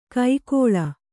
♪ kai kōḷa